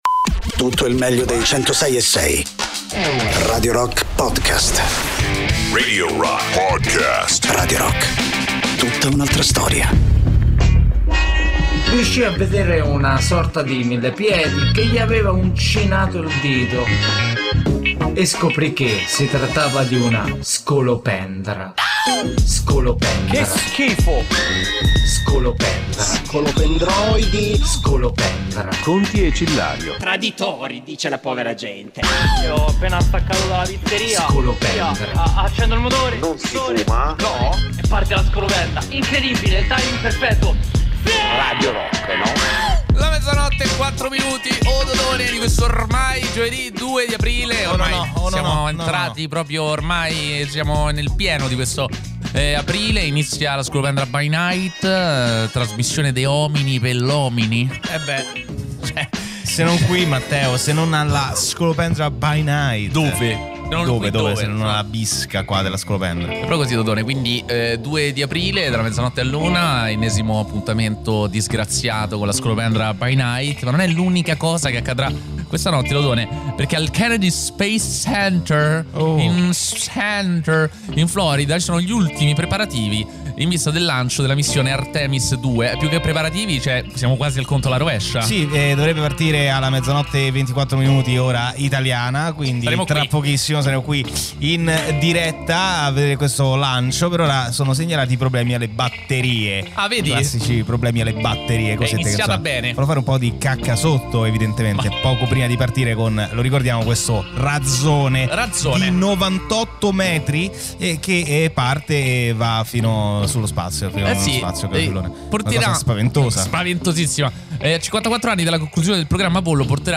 in diretta Sabato e Domenica dalle 15 alle 18